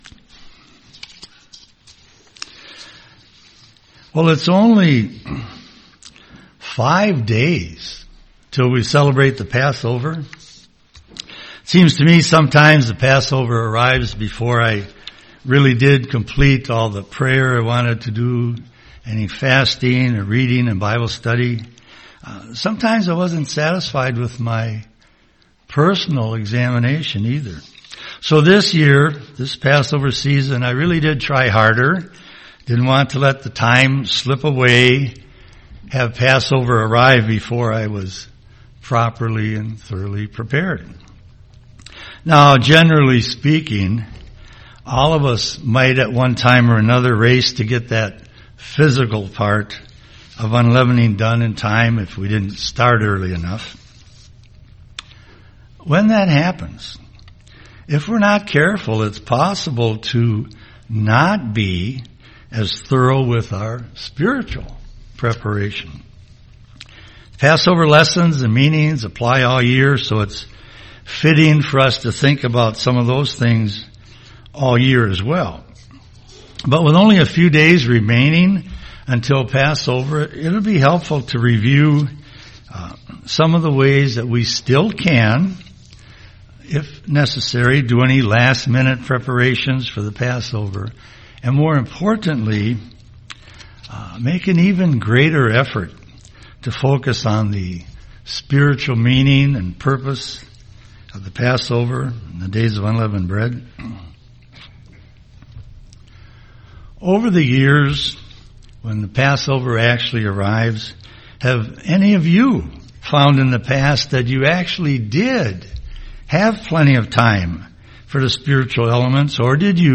UCG Sermon Days of Unleavened Bread Passover Leavening Studying the bible?